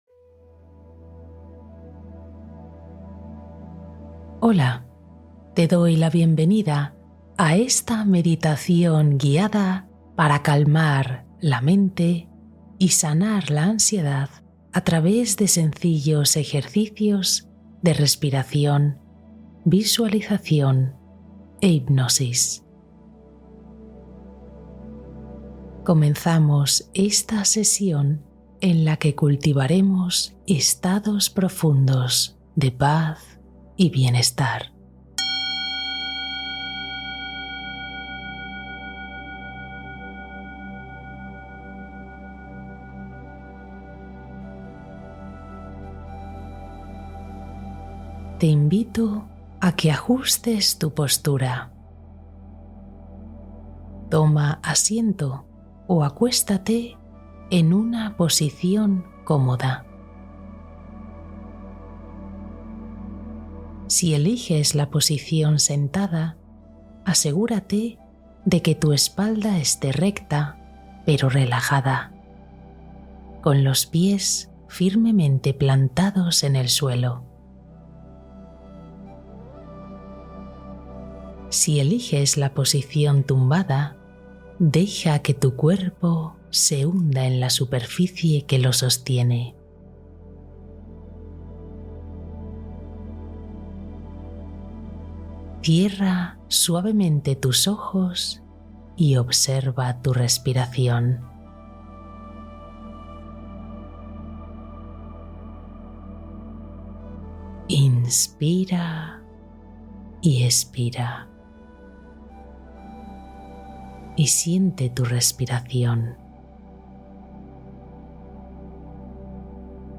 Mente en Paz | Meditación Guiada Para Dormir y Soltar Ansiedad